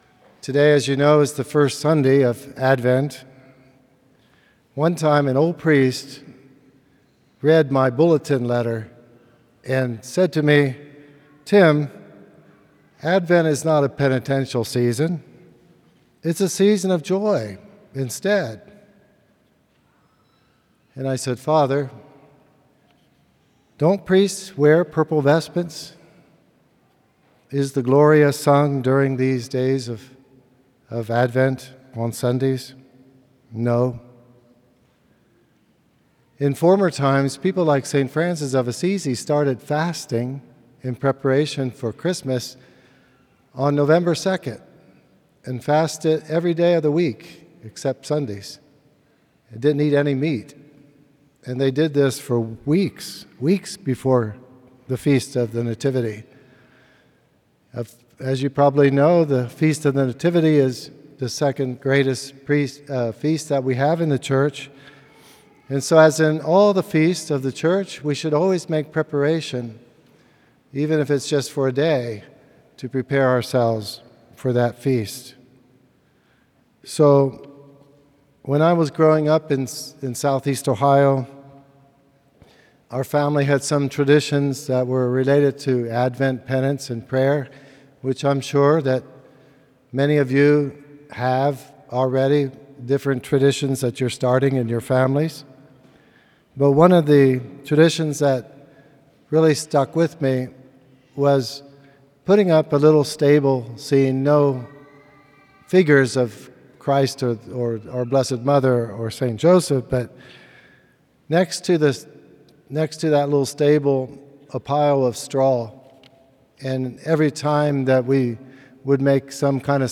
From Series: "Homilies"
Homilies that are not part of any particular series.